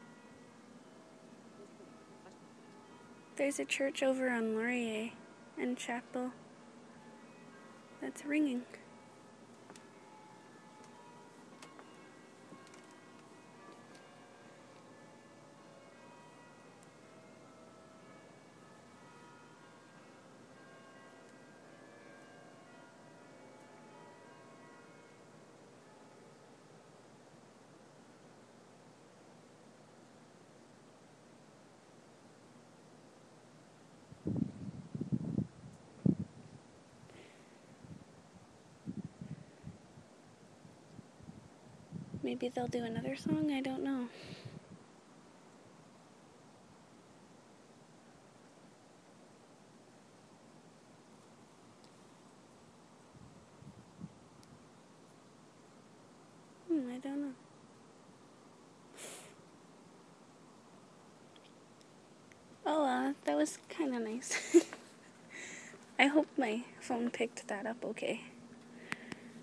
Church bells ringing